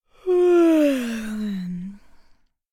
female_yawn1.ogg